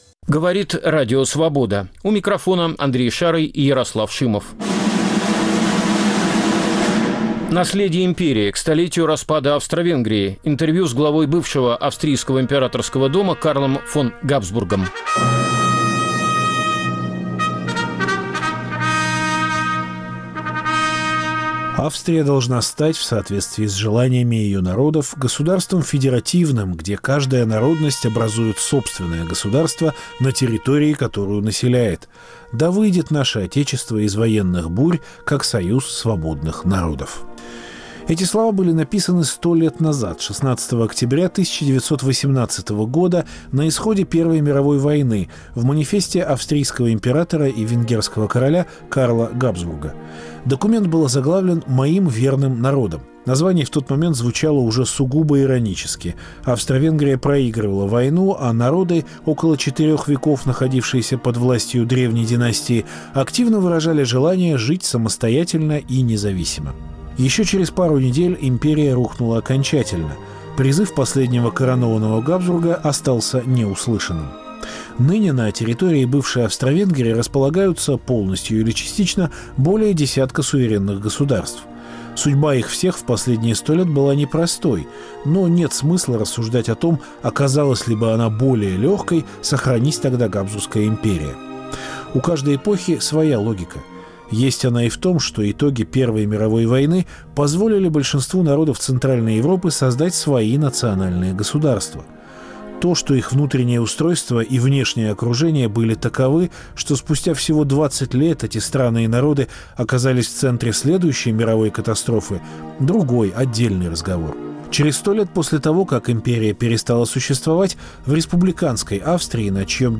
Интервью с главой дома Габсбургов Карлом фон Габсбургом. К столетию распада Австро-Венгрии